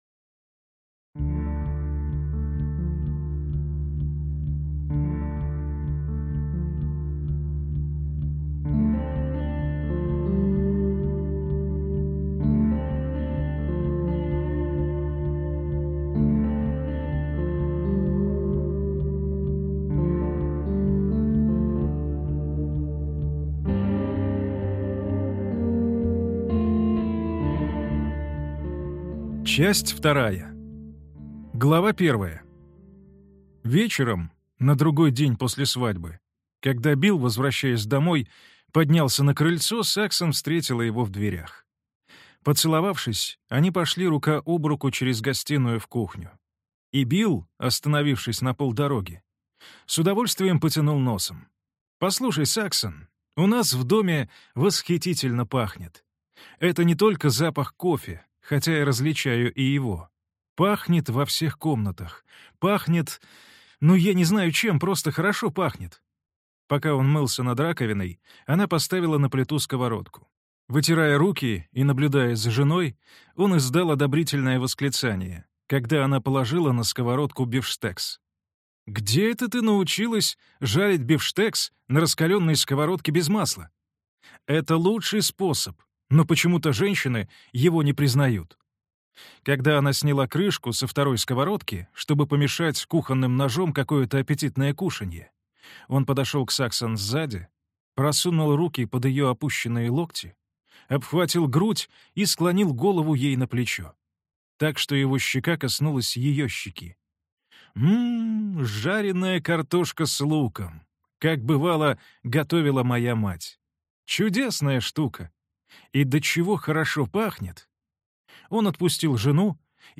Аудиокнига Лунная долина. Часть 2 | Библиотека аудиокниг